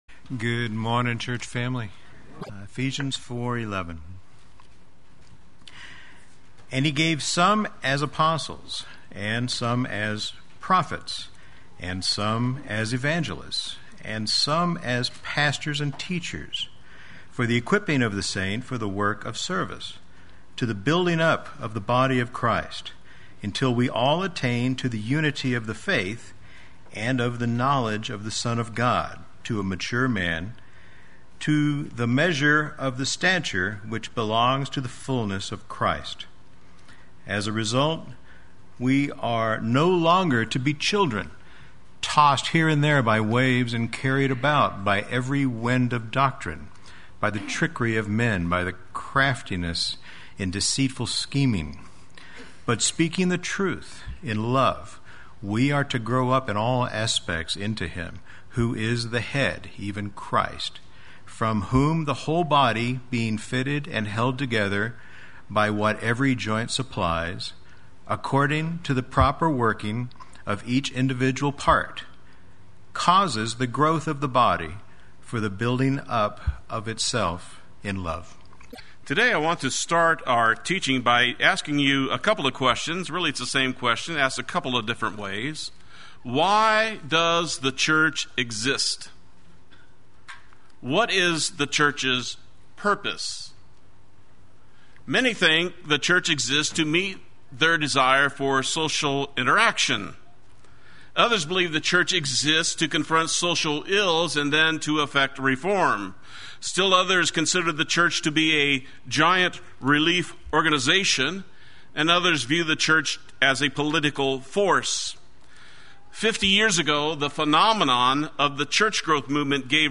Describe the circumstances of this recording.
“The Fullness of Christ” Sunday Worship